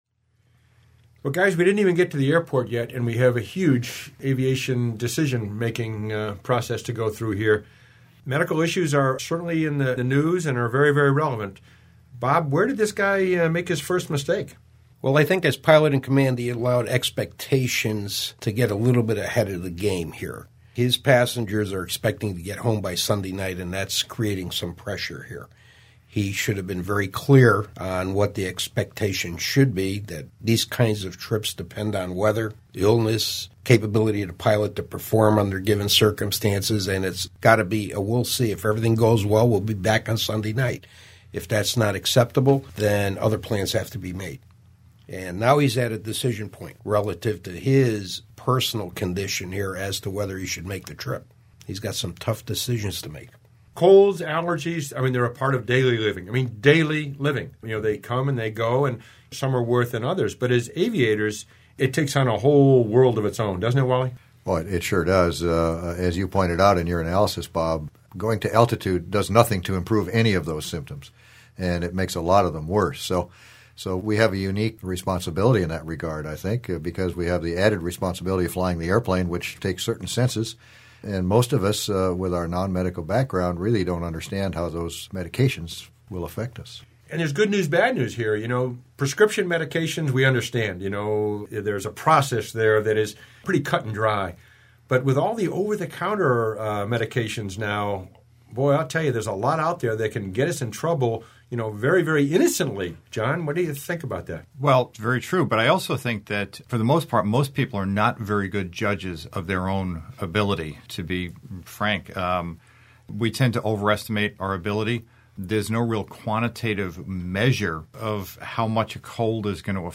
44_roundtable.mp3